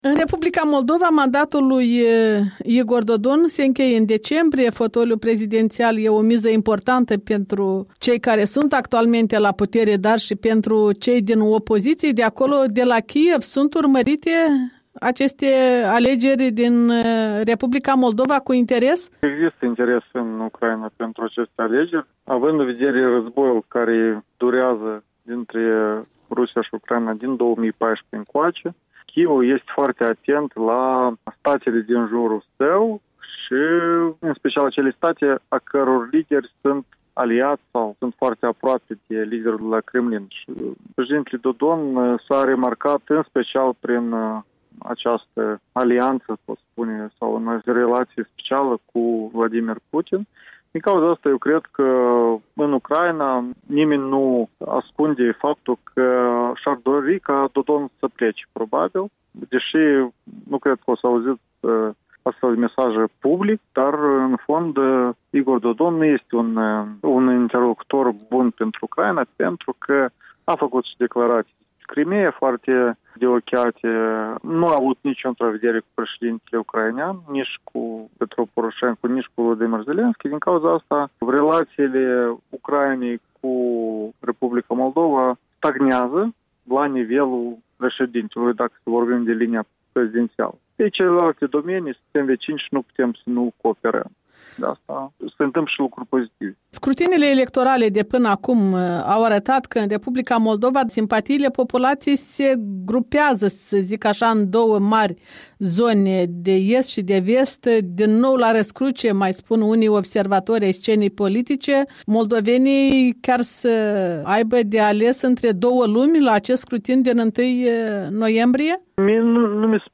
Interviu electoral